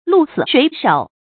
注音：ㄌㄨˋ ㄙㄧˇ ㄕㄨㄟˊ ㄕㄡˇ
鹿死誰手的讀法